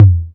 40 Shebib Perc.wav